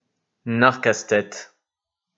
Narcastet (French pronunciation: [naʁkastɛt]